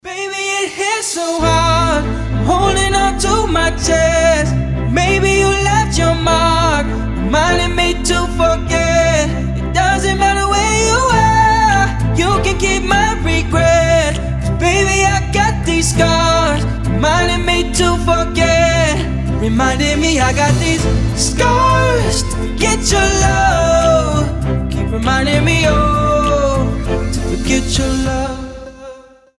• Качество: 320, Stereo
красивый мужской голос
dance
Electronic
EDM
романтичные